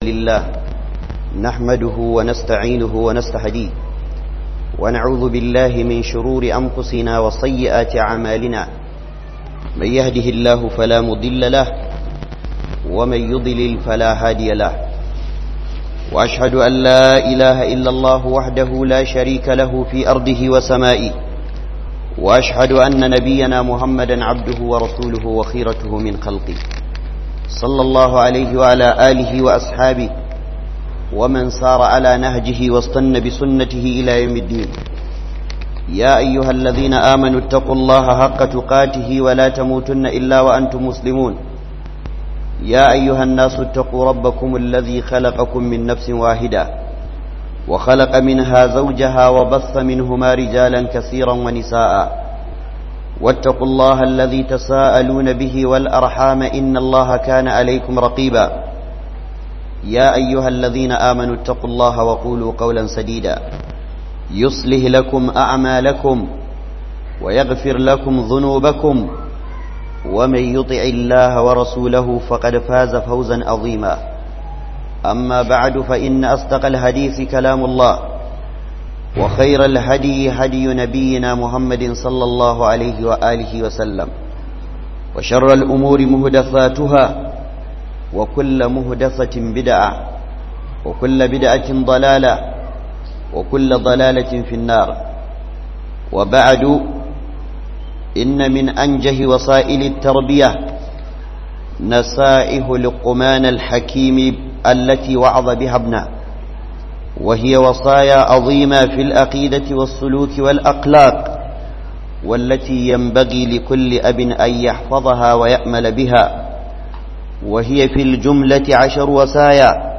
WASIYYA BIYAR DAGA LUKMANUL HAKIM - Huduba